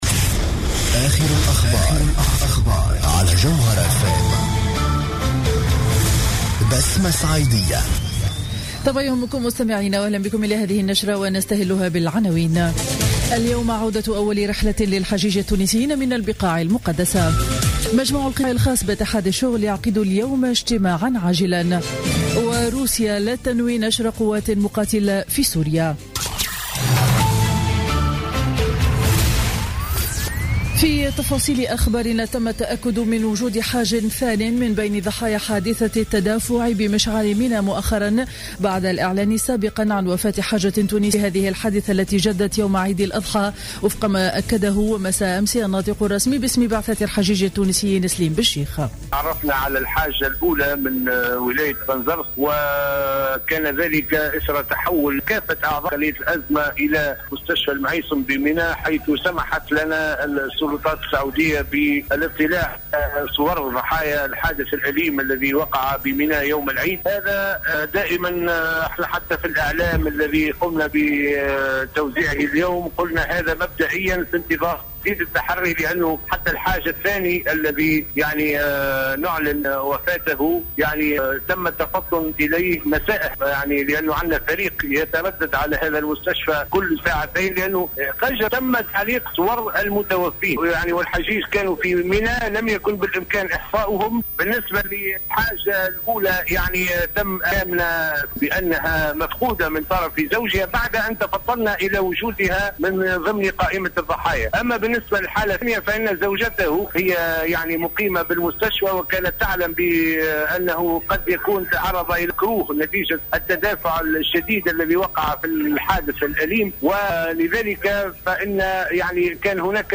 Journal Info 07h00 du lundi 28 septembre 2015